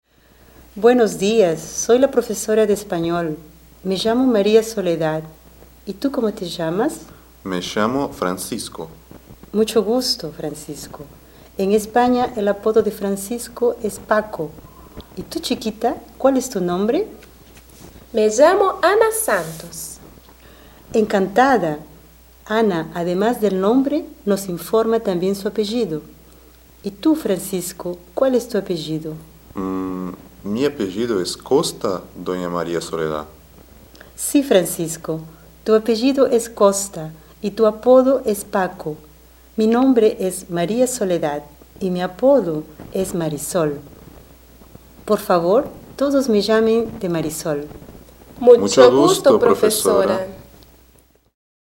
Description: Áudio do livro didático Língua Espanhola I, de 2008. Diálogo de apresentação.